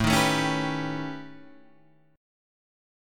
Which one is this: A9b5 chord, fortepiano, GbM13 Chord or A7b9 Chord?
A9b5 chord